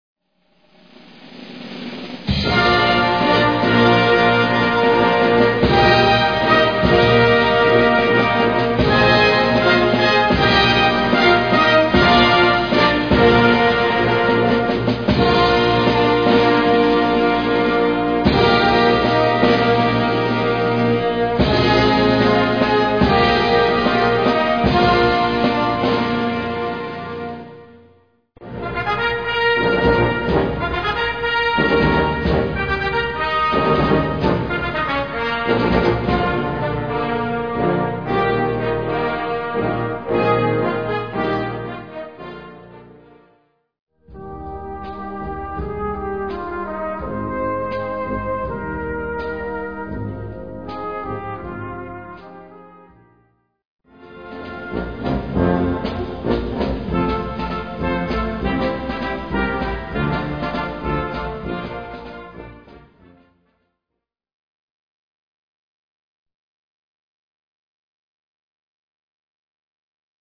Gattung: Selection
Besetzung: Blasorchester